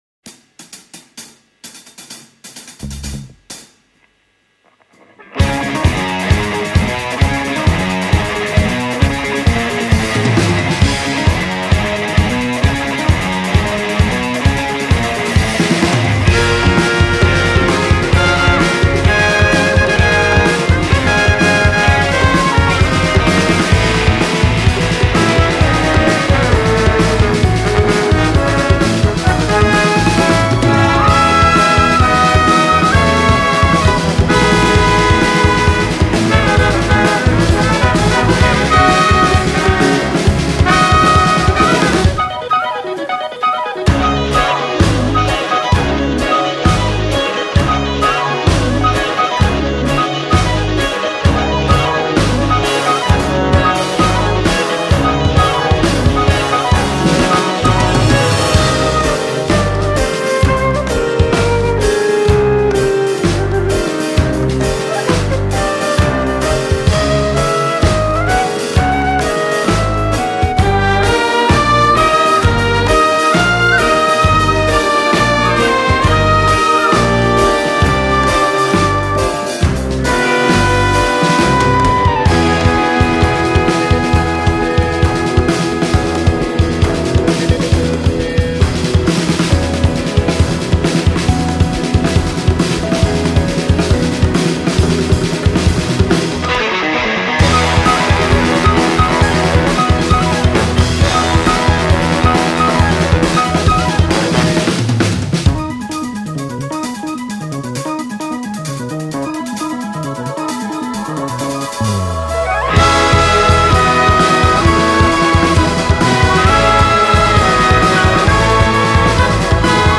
Experimental cinematic dance outfit from Melbourne Australia
is a chaotic blend of various sounds